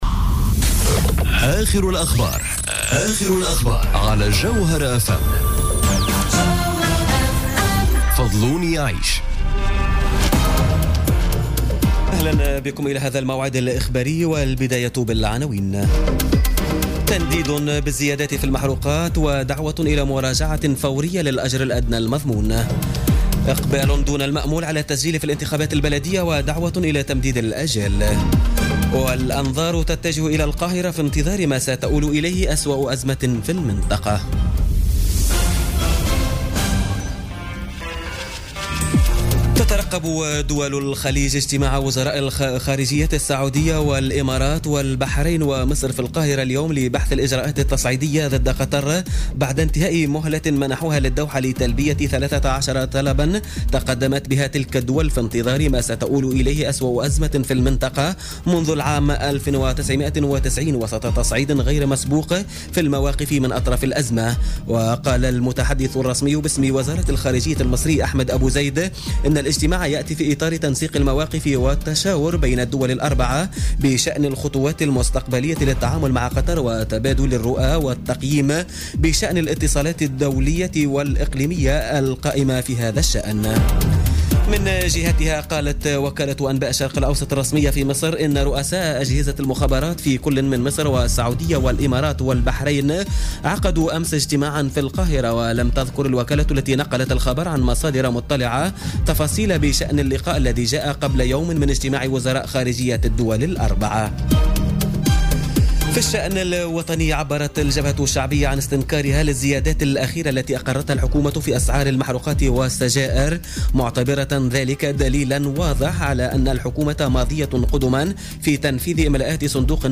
نشرة أخبار منتصف الليل ليوم الإربعاء 5 جويلية 2017